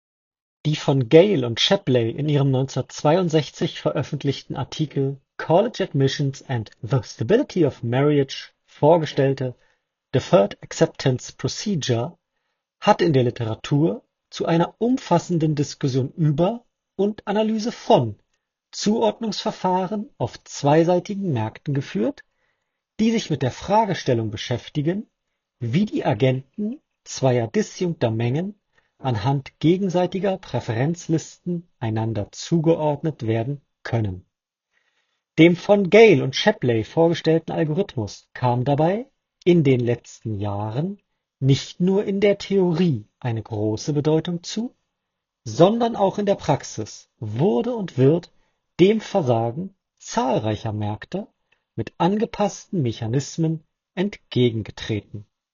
Das können anderen Kopfhörer mitunter deutlich besser, allen voran der Sony WH-1000XM6, der in diesem Bereich ein hervorragendes Ergebnis abgeliefert hat.
Sony WH-1000XM6 – Mikrofonqualität